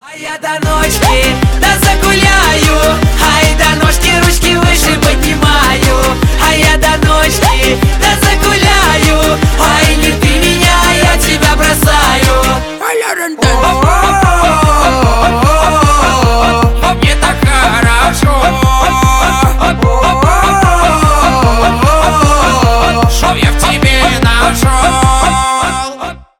бесплатный рингтон в виде самого яркого фрагмента из песни
Поп Музыка
весёлые